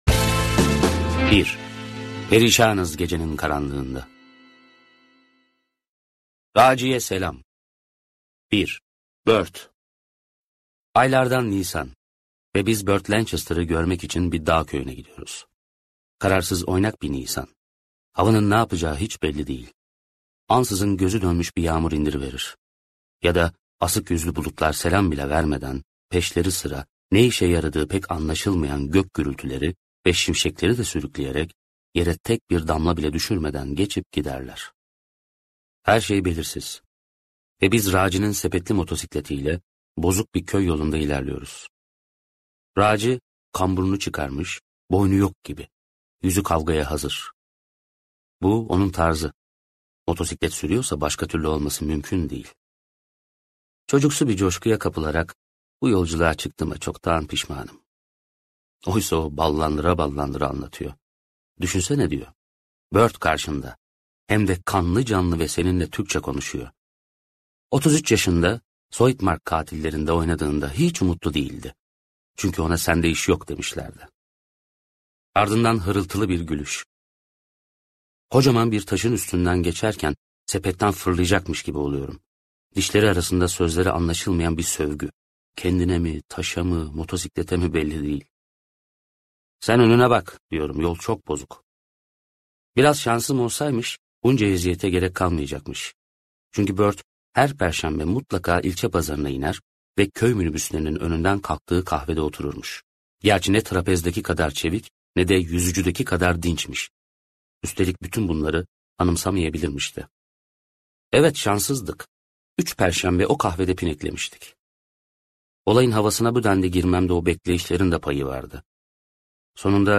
Uzak Noktalara Doğru - Seslenen Kitap
Seslendiren